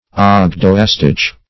Search Result for " ogdoastich" : The Collaborative International Dictionary of English v.0.48: Ogdoastich \Og`do*as`tich\, n. [Gr.